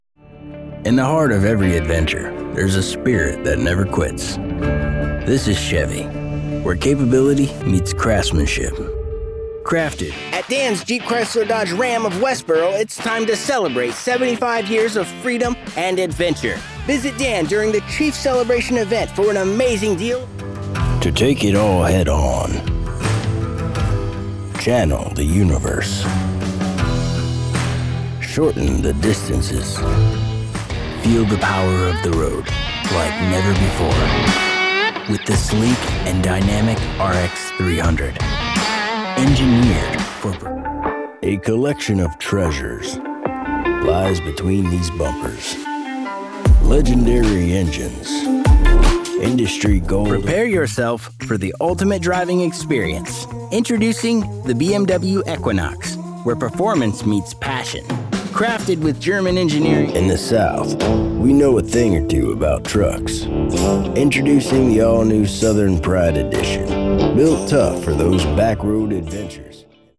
Automotivo
I am known for the conversational personality, animated acting and raspy sound I bring to the performance.
I have a professional recording studio at home as well as strong mixing & mastering skills.
Sennheiser MKH416 shotgun microphone